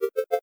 Success1.wav